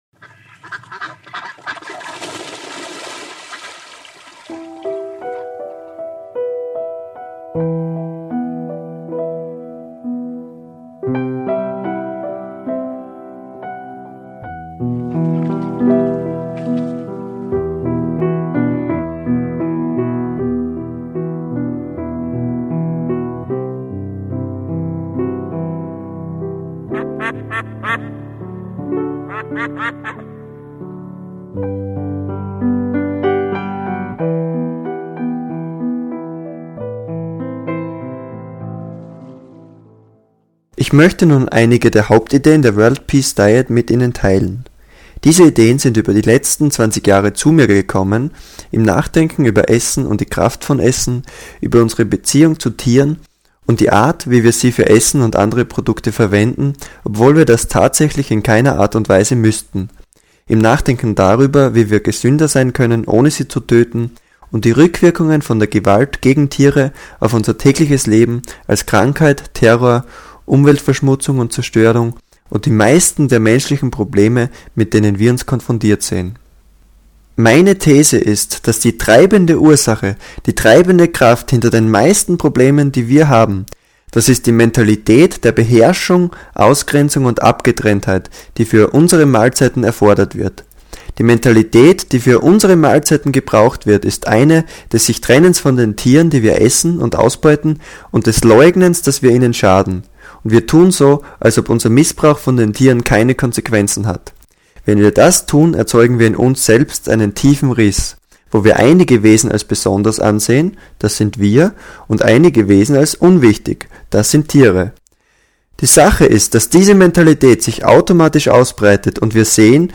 is a discourse, with short musical interludes